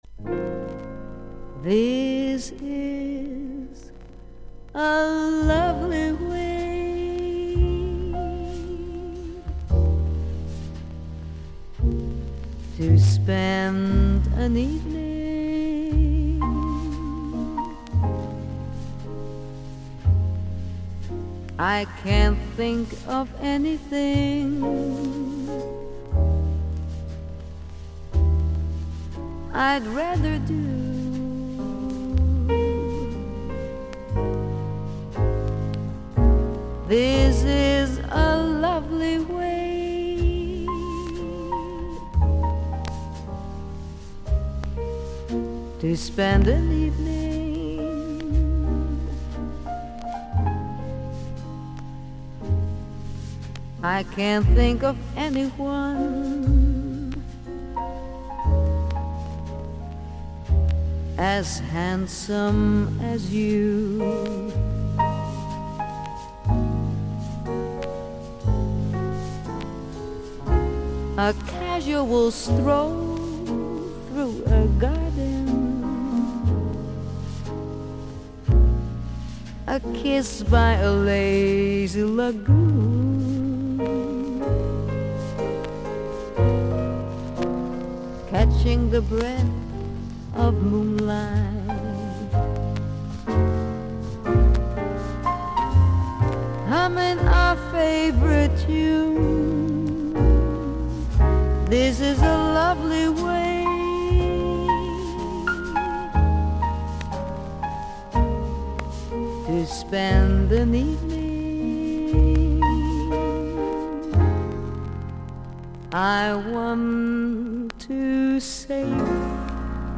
少々サーフィス・ノイズあり。クリアな音です。
オランダのモダン・ジャズ・シンガー。クールで気怠い独特のムードを持った声。